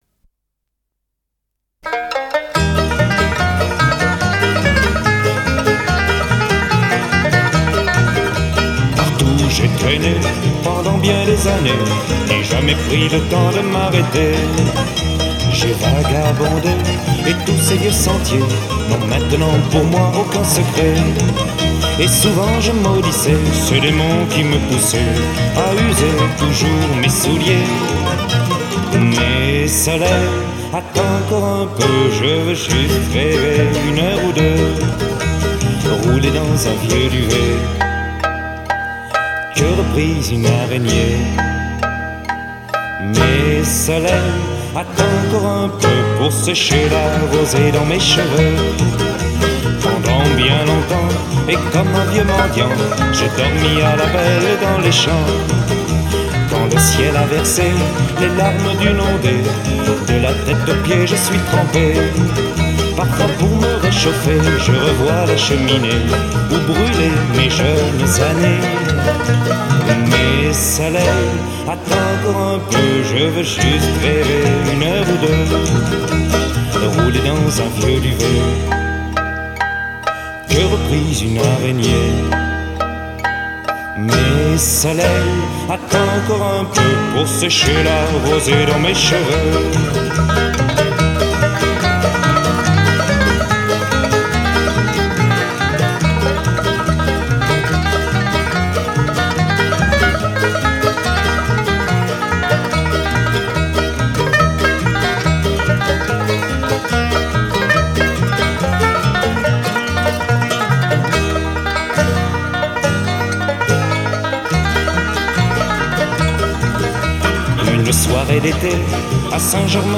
• Guitares
• Banjo
• Harmonica
• Contrebasse
• Mandoline américaine